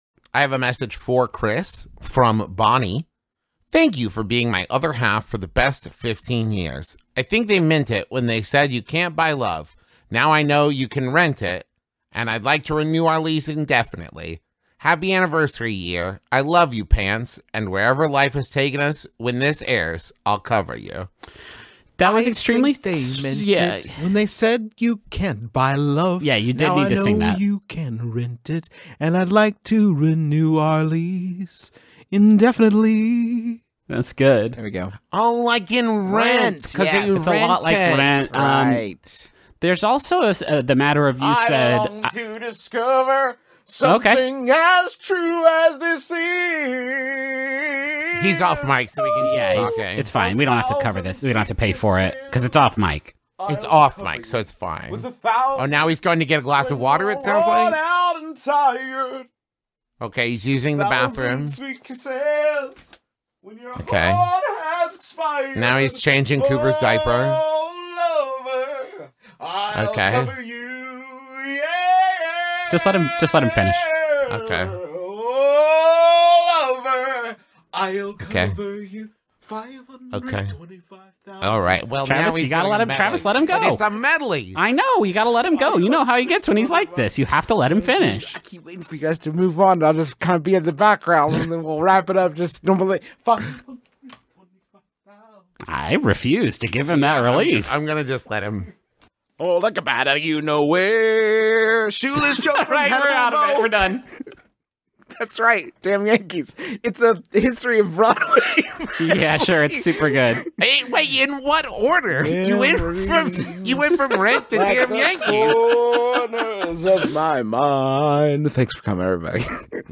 a jumbotron message provokes justin to sing rent while griffin and travis beg him to stop